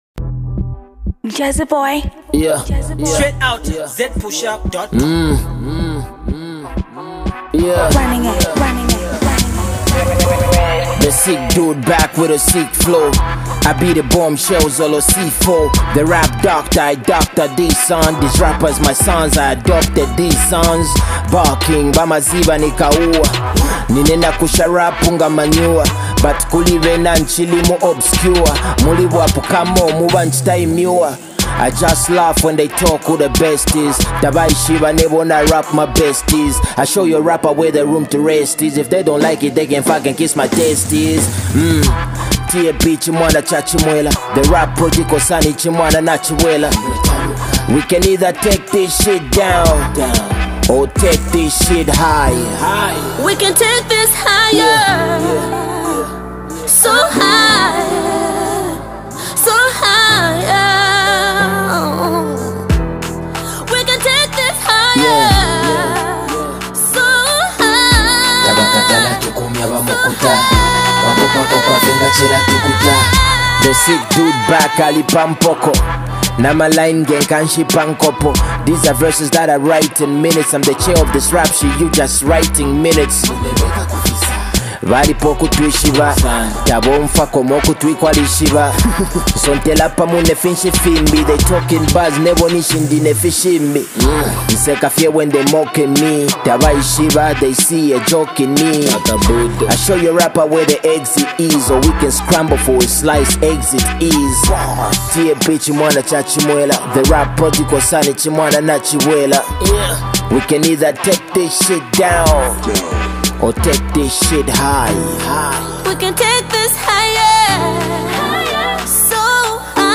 hiphop jam